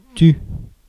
Ääntäminen
France: IPA: /ˈty/